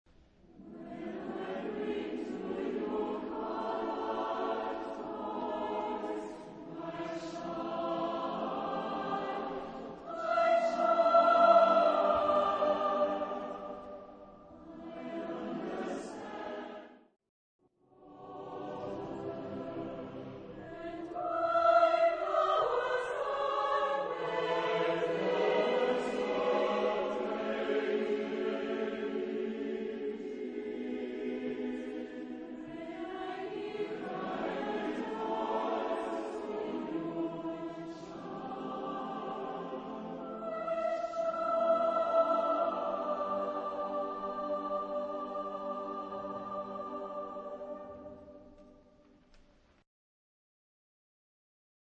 Tonality: E major